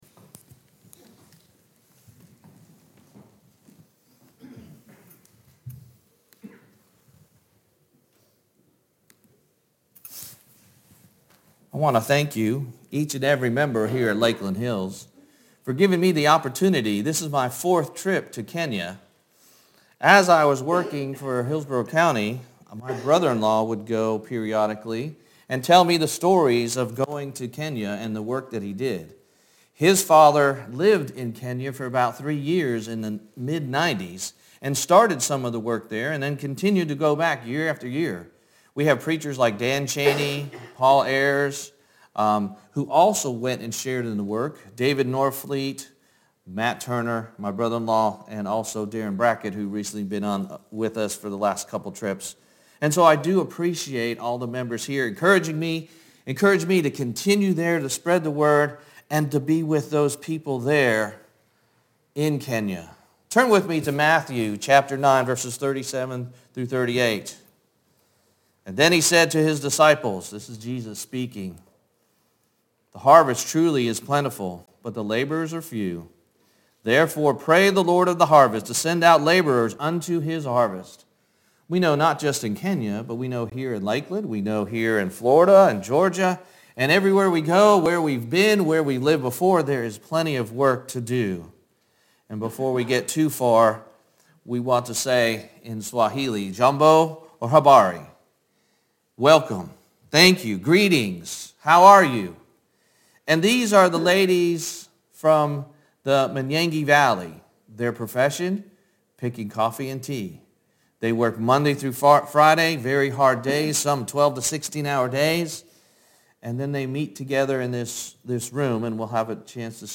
Sun PM Bible Sermon – Report on Kenya